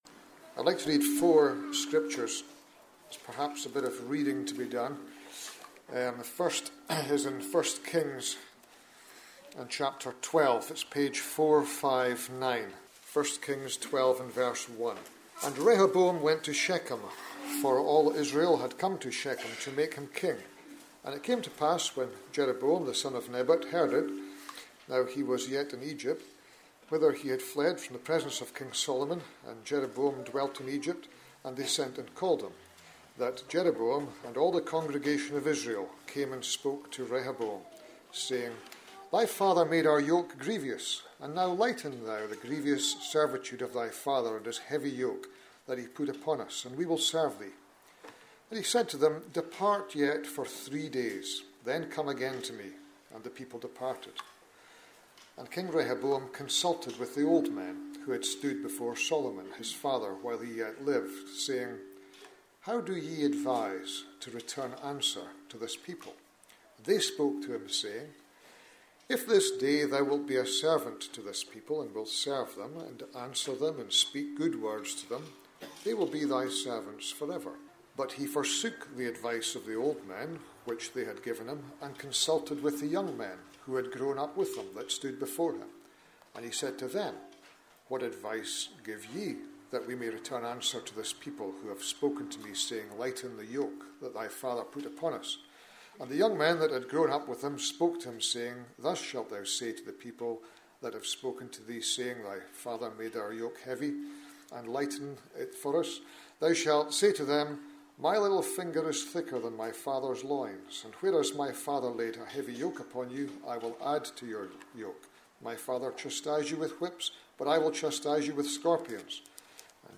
In this address, you will hear of 4 examples where men and women who had an influence for good and evil.
Influence-Address-Worthing.mp3